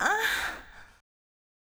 Vox (Metro Moan).wav